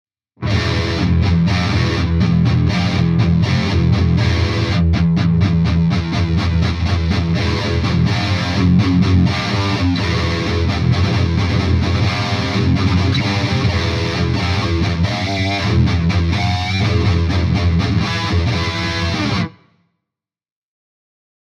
Tutte le clip audio sono state registrate con amplificatori reali iniziando con Ignition spento nei primi secondi per poi accenderlo fino alla fine della clip.
Chitarra: Fender Stratocaster (pickup al ponte)
Testata: Mesa Dual Rectifier sul canale 3 nella modalità Modern
Cassa: Mesa XXL con coni Celestion V30